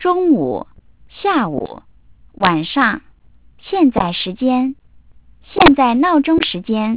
The input to the decoder is buffered and when a frame erasure is detected, samples delayed by 10 ms were introduced to the decoder input.
- sampling rate : 8 kHz
reconstructed speech